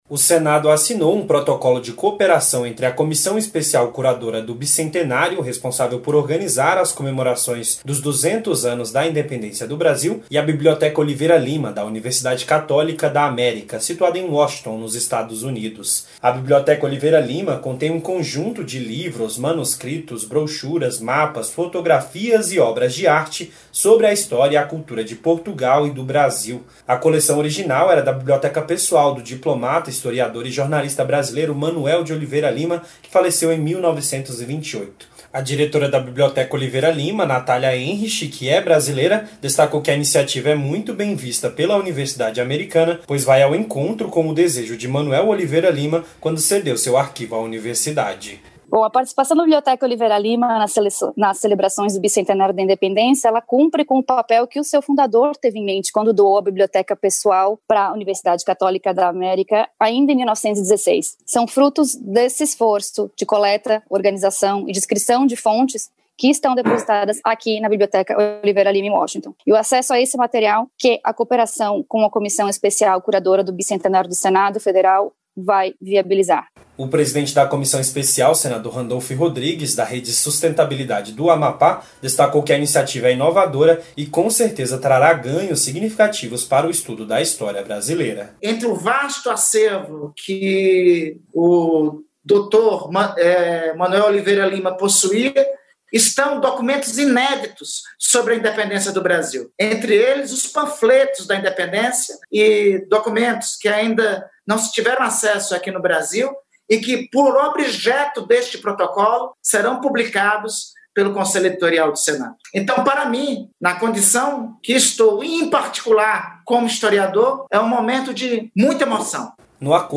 O Senado assinou na última segunda-feira (17) um protocolo de cooperação entre a Comissão Especial Curadora do Bicentenário, responsável por organizar as comemorações dos 200 anos da Independência do Brasil, e a Biblioteca Oliveira Lima, da Universidade Católica da América, situada em Washington. A reportagem